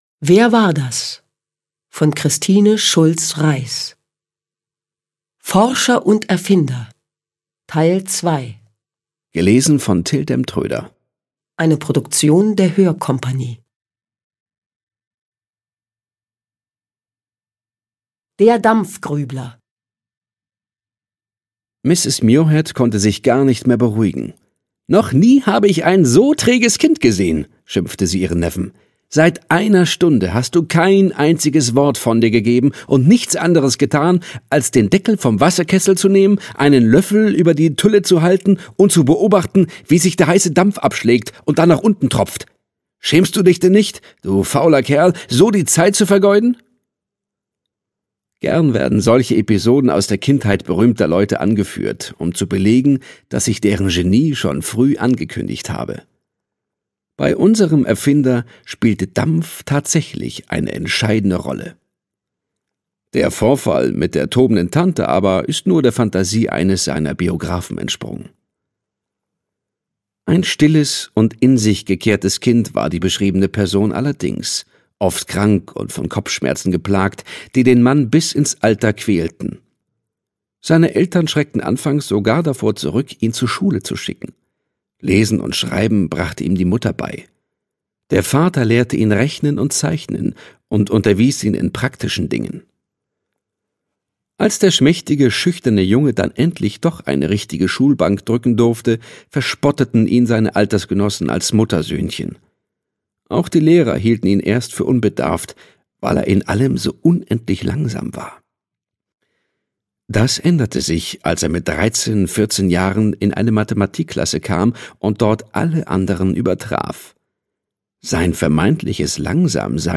Sprecher: Till Demtrøder, 1 CD, Jewelcase
Till Demtrøder (Sprecher)